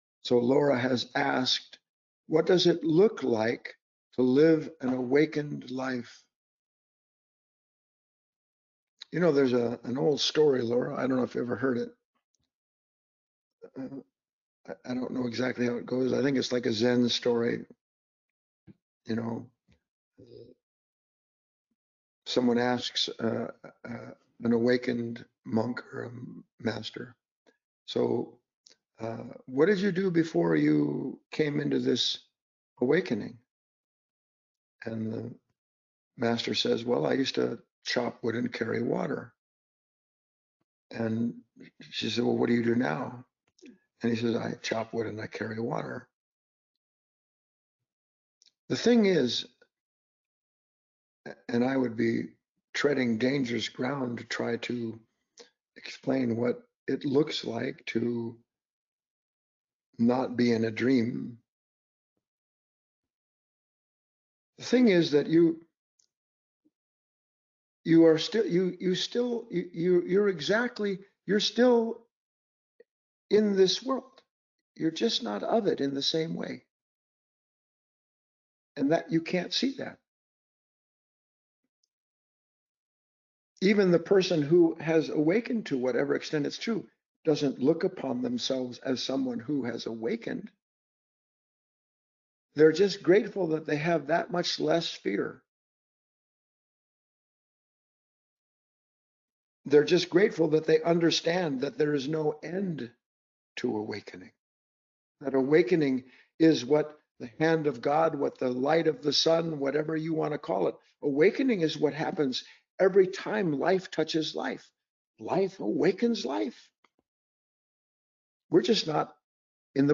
In this short talk